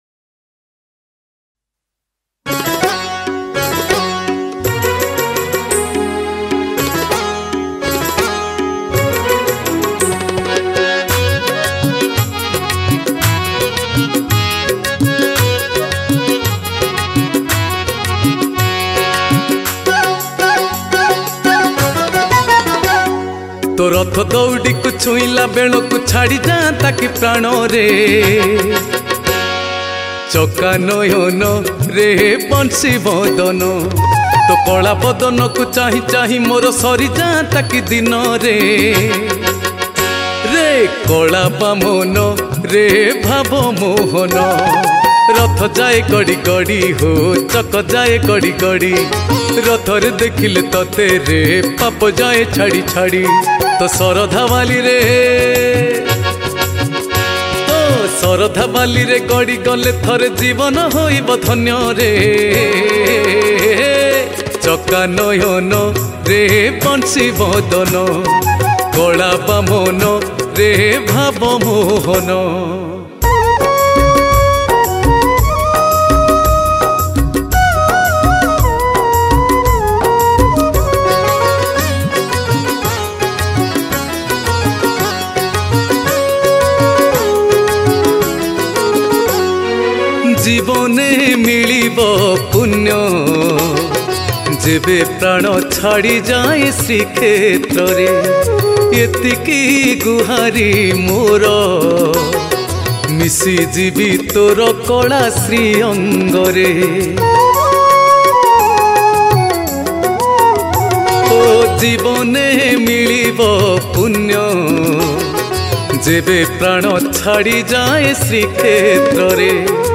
Ratha Yatra Odia Bhajan 2022 Songs Download